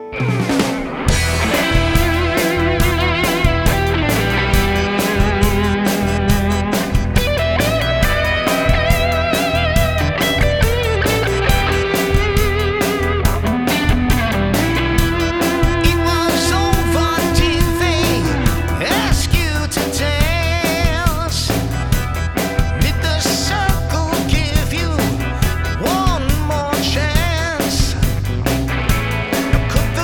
Keyboards, Lead Guitar, B/G vocals